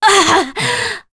Demia-Vox_Damage_07.wav